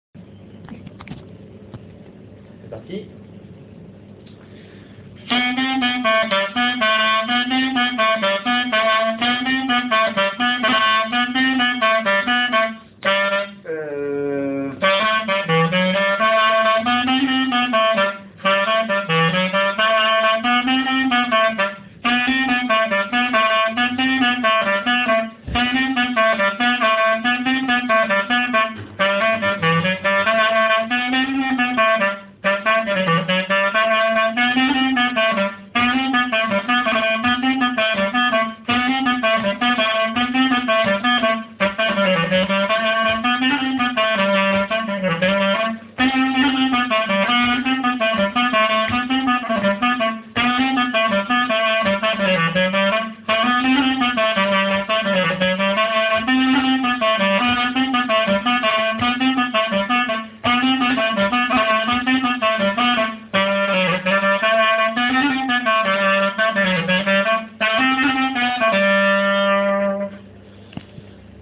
:mp3:2010:stages:clarinette
clarinette-loudeac_1.mp3